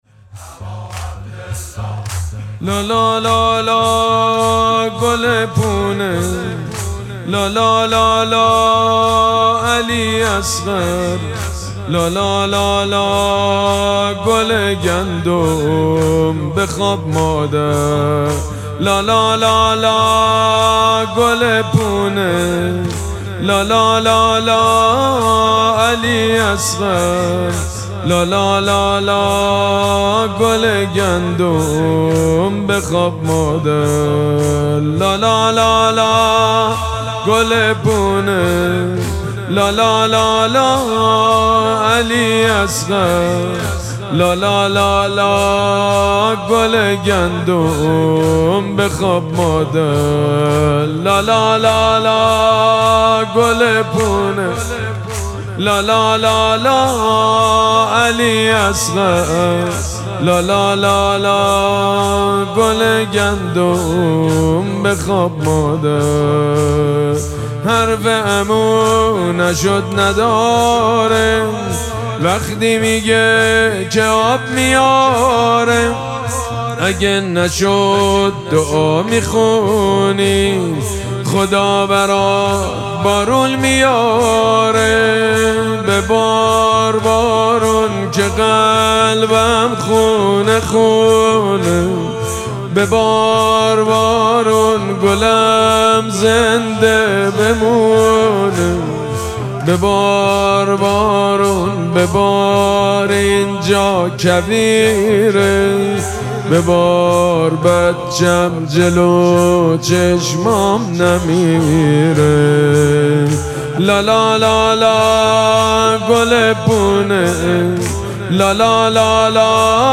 مراسم مناجات شب هفتم ماه مبارک رمضان
مداح
حاج سید مجید بنی فاطمه